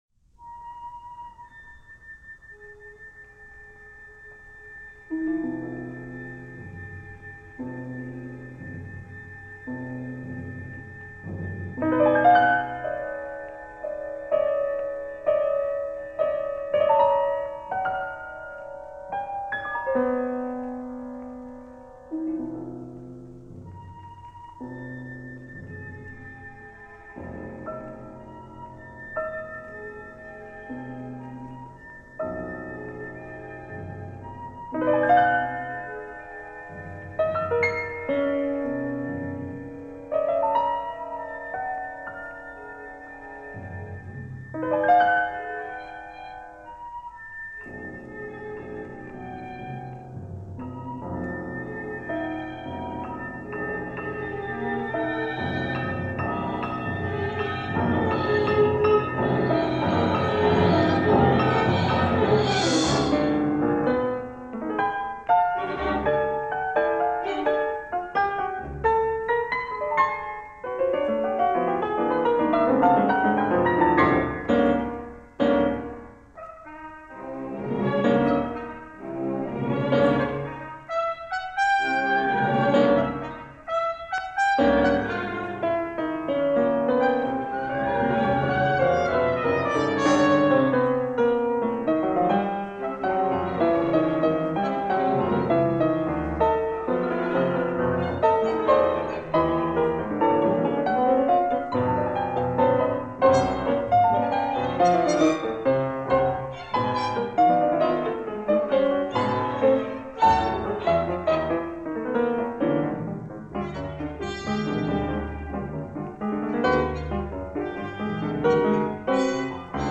broadcast studio performance